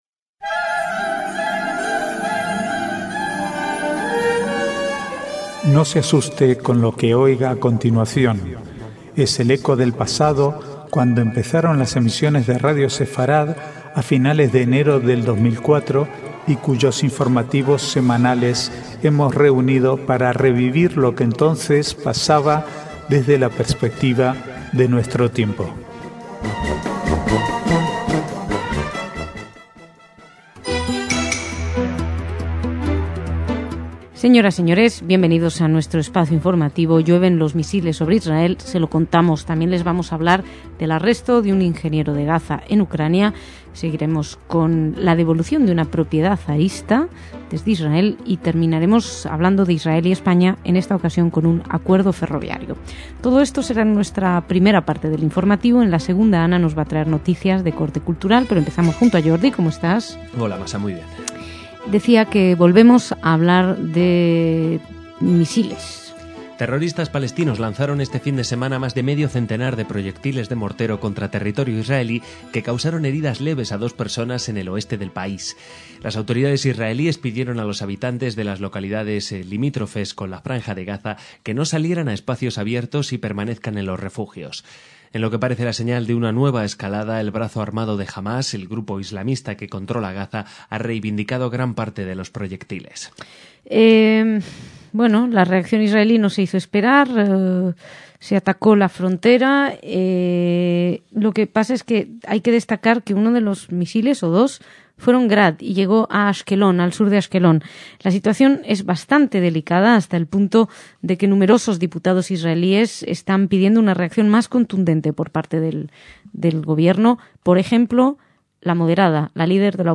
Archivo de noticias del 22 al 25/3/2011